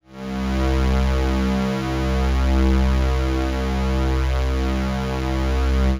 C2_trance_pad_2.wav